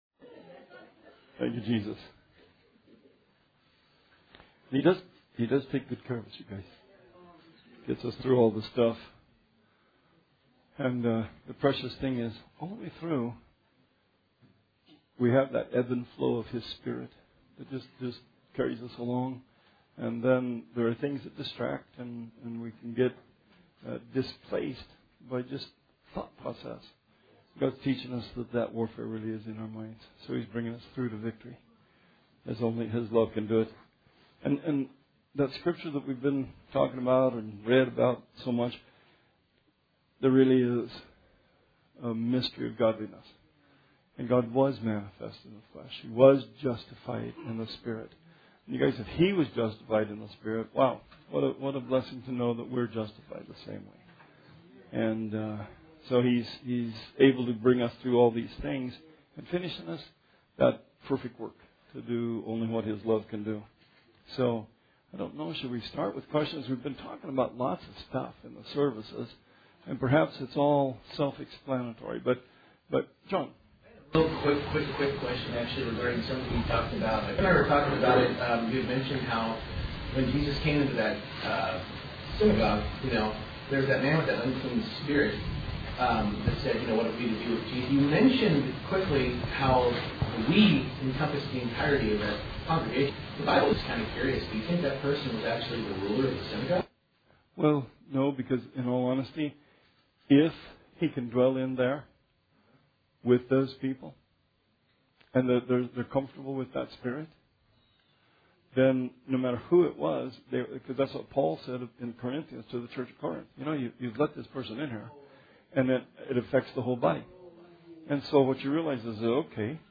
Bible Study 2/26/20